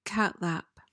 PRONUNCIATION: (KAT-lap) MEANING: noun: A watery drink, especially weak tea or milk.